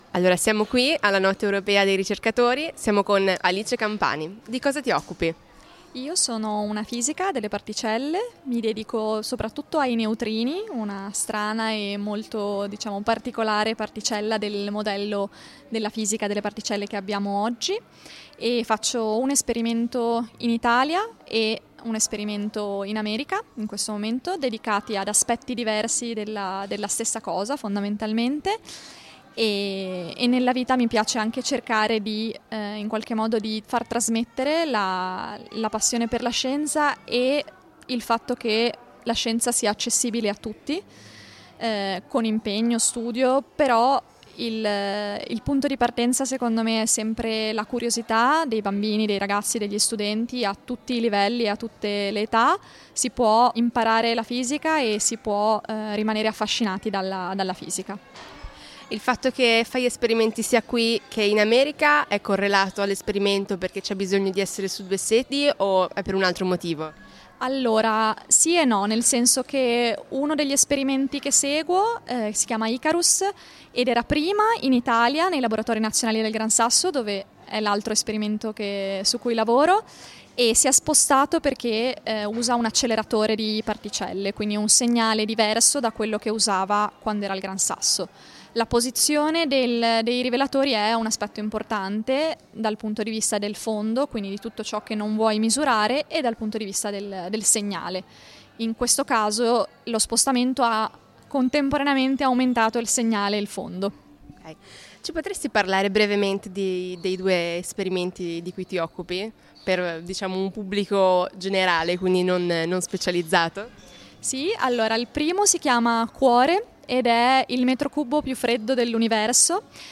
SHARPER: Notte Europea dei Ricercatori e delle Ricercatrici 2024
intervista